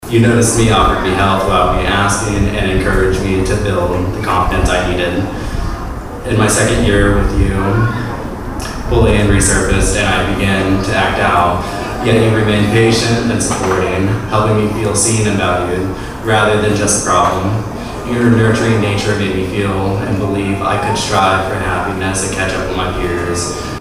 Warm temperatures and high winds couldn't keep a crowd from filling Bill Doenges Memorial Stadium Thursday night, as the baseball-themed Bruins of the Year ceremony celebrated teachers and staff from Bartlesville Public Schools.